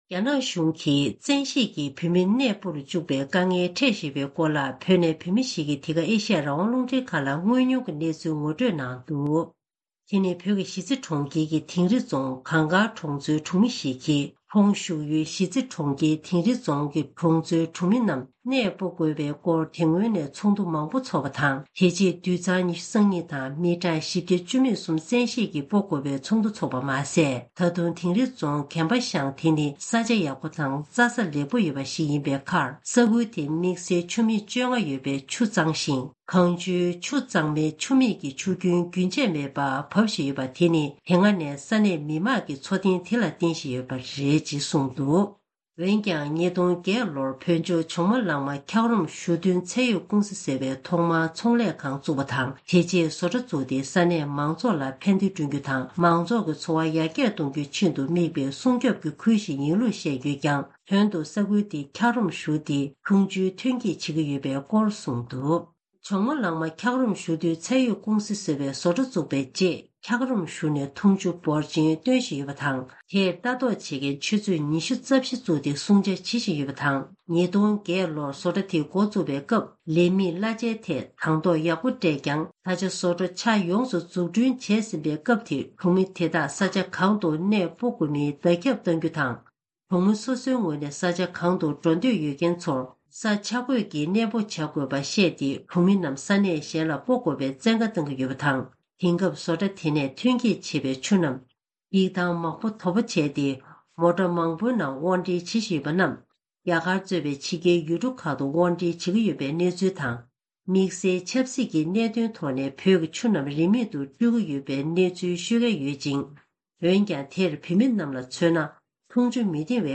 སྒྲ་ལྡན་གསར་འགྱུར། སྒྲ་ཕབ་ལེན།
ཐེངས་འདིའི་གསར་འགྱུར་དཔྱད་གཏམ་གྱི་ལེ་ཚན་ནང་རྒྱ་ནག་གཞུང་གིས་བོད་ནང་གི་འཁྱག་རོམ་ཞུར་ནས་འཐུང་ཆུ་བཟོ་བའི་བཟོ་གྲྭ་བཙུགས་ཏེ་ས་གནས་ཡུལ་མི་རྣམས་གནས་སྤོ་དགོས་པའི་བཙན་བཀའ་གཏོང་བཞིན་ཡོད་པའི་སྐོར་བོད་ནས་བོད་མི་ཞིག་གིས་འདི་ག་ཨེ་ཤི་ཡ་རང་དབང་རླུང་འཕྲིན་ཁང་ལ་གནས་ཚུལ་དངོས་མྱོང་ངོ་སྤྲོད་གནང་བ་ཞིག་གསན་རོགས་གནང་།